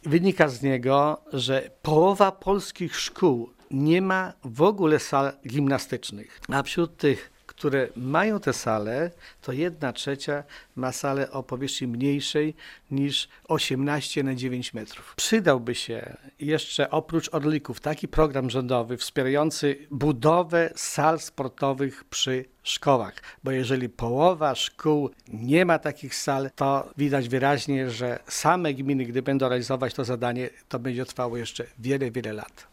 Jednym ze zwolenników takiego rozwiązania jest Wiktor Osik, zastępca wójta wiejskiej Gminy Łuków, której udało się wybudować sale niemal w każdej szkole. Wiktor Osik powołuje się na najnowszy raport Najwyższej Izby Kontroli: